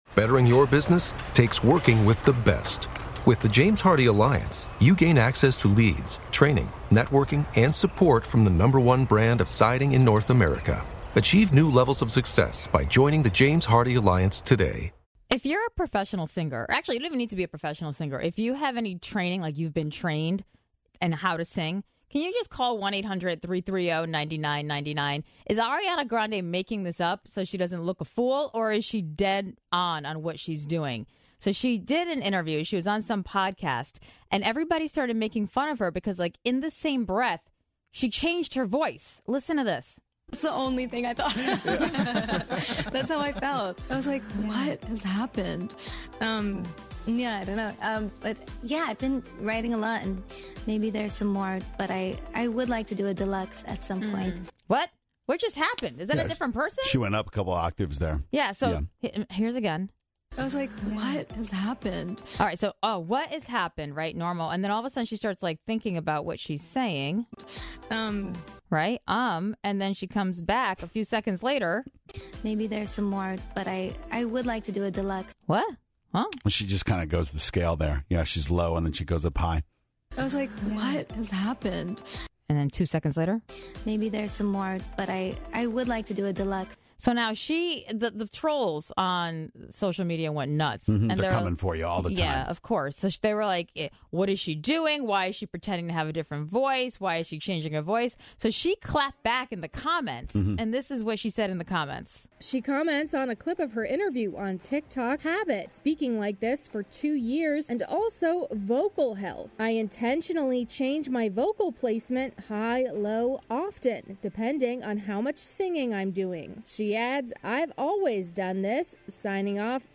We asked you, the pros, and there was a lot of singing involved.